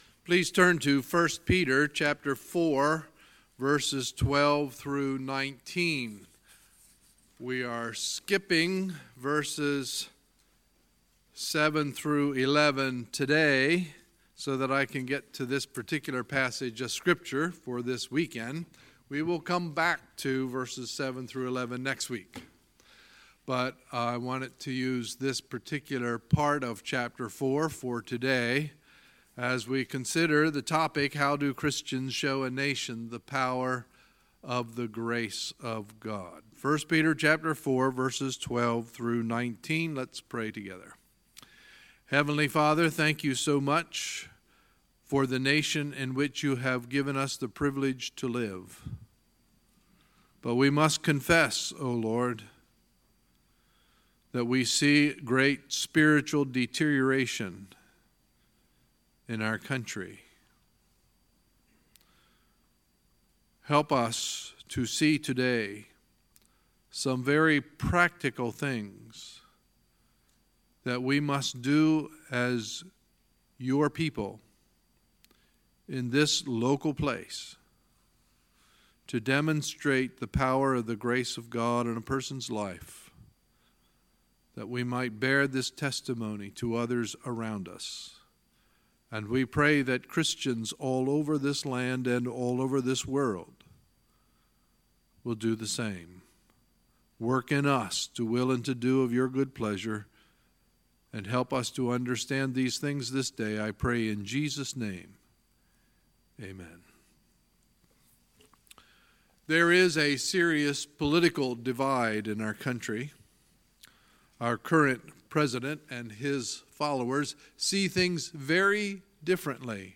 Sunday, July 1, 2018 – Sunday Morning Service